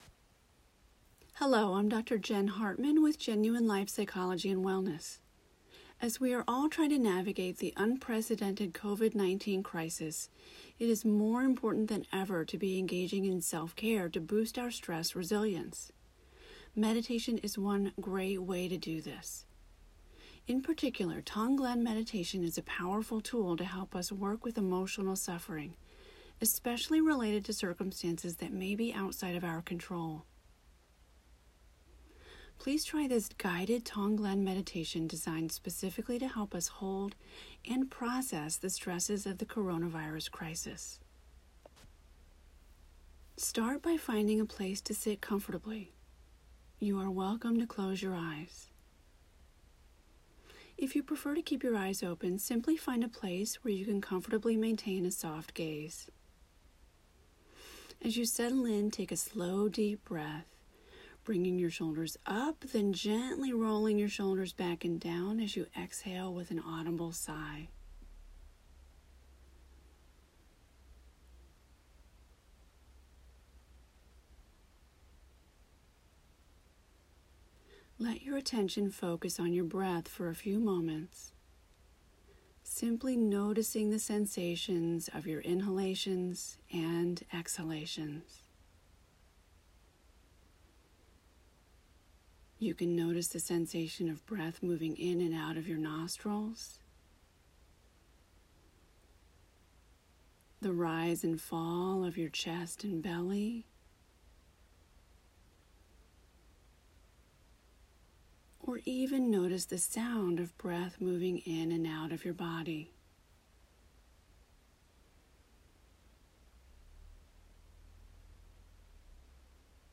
I have just recorded and posted a new guided meditation on my website here:
Tonglen-Meditation-for-Coronavirus-Stress.m4a